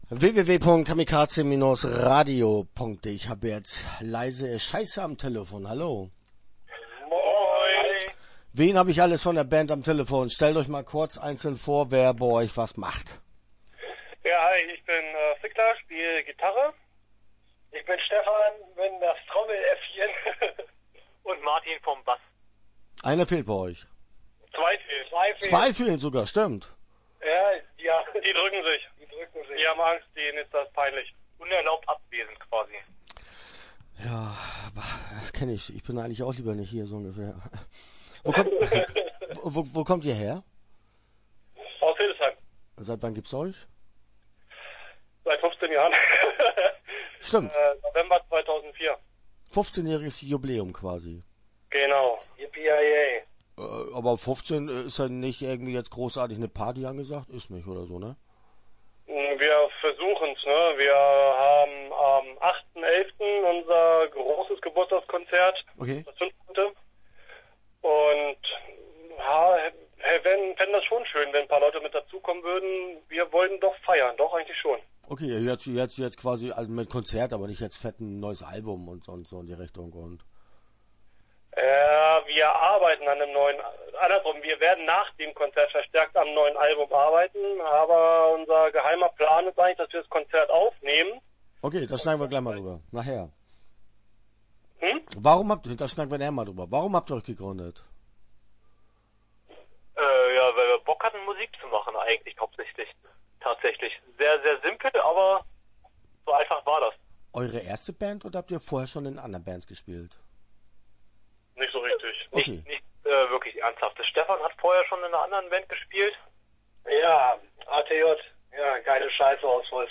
Start » Interviews » Leise iss schaiße